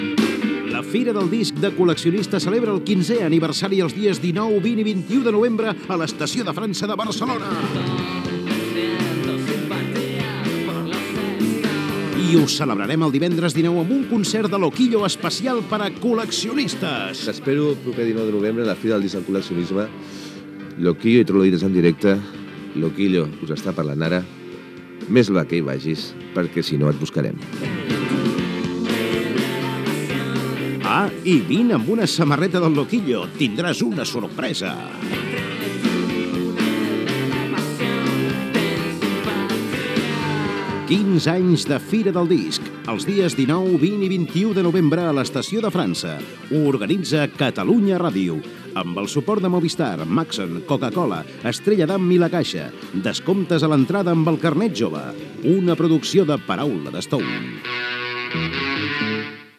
Anunci de la 15ena Fira del Disc de Col·leccionista a Barcelona, inclou un missatge del cantant Loquillo